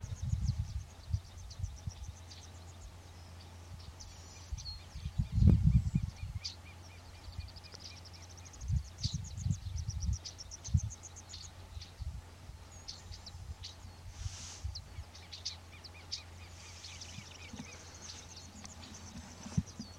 Pampas Pipit (Anthus chacoensis)
Location or protected area: Concordia
Condition: Wild
Certainty: Observed, Recorded vocal